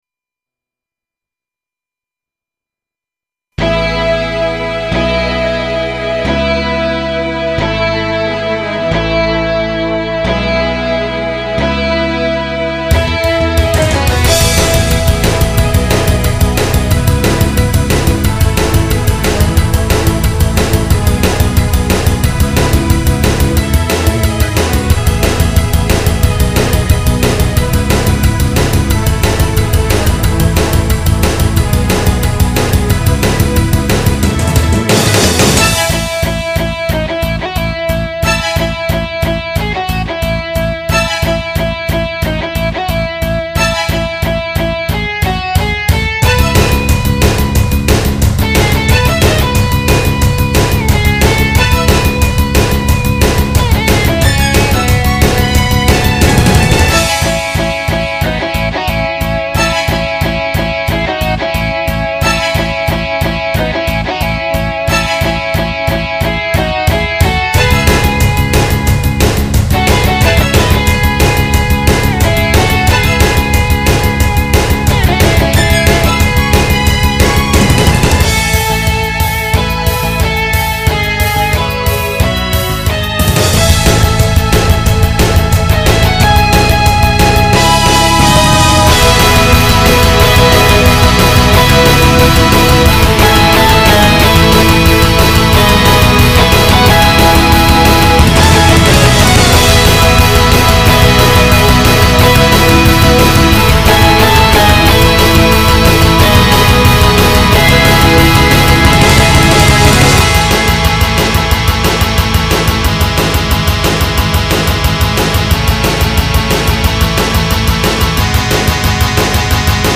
ハード音源使ってはじめて書いた曲が 別れの歌 だったってのは覚えてるんですけどねえ、、、笑 ちなみにこれ、高校生になったばっかの時に書いた曲です。
この頃はまだベロシティすらいじってないんですけど(笑) 長年Dominoを使ってきた身としては、Dominoは完成されたMIDIシーケンサーであるということです。